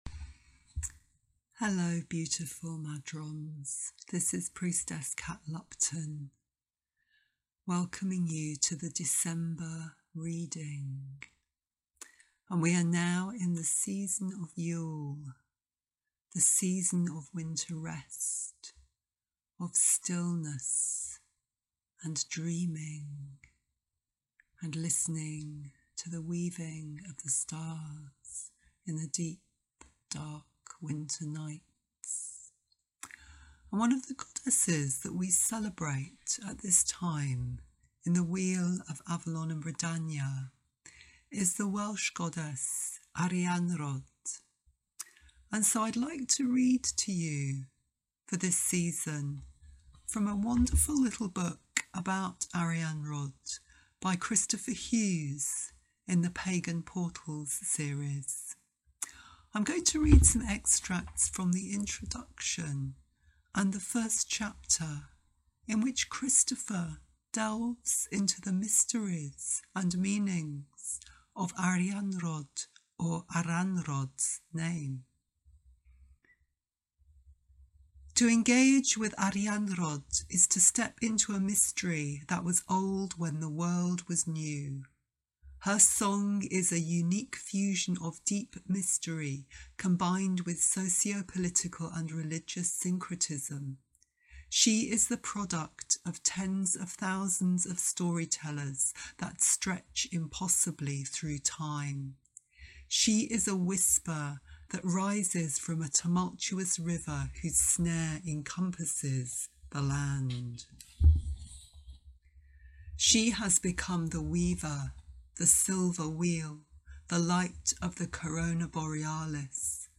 New Monthly Reading